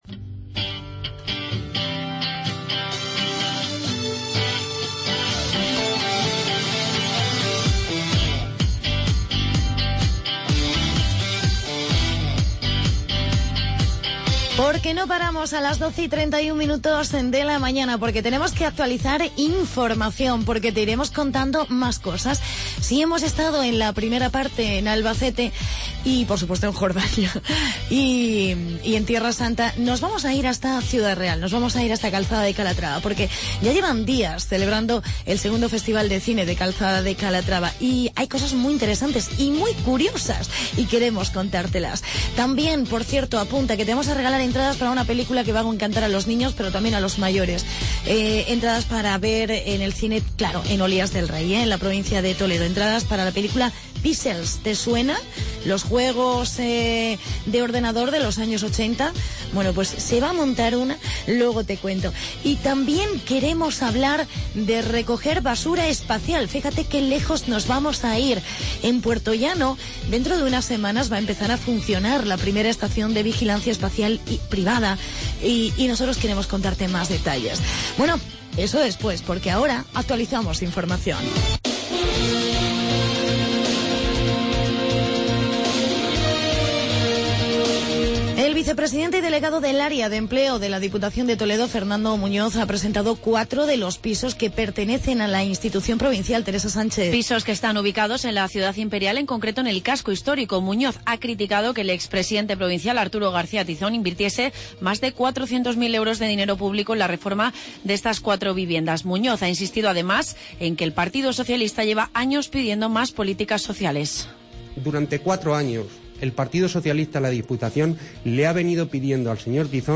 Hablamos con el alcalde Félix Martín del Festival de cine de Calzada de Calatrava y reportaje sobre la estación de Basura Espacial.